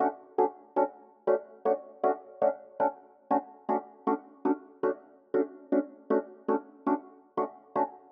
12 ElPiano PT3.wav